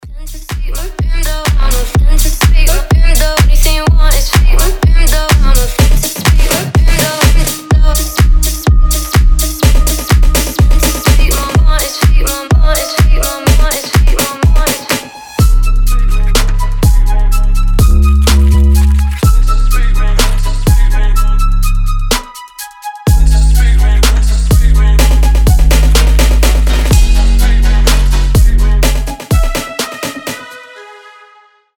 • Качество: 320, Stereo
ритмичные
женский голос
Electronic
EDM
мощные басы
Bass House
качающие
Крутой клубняк, сочетающий хаус и трап музыку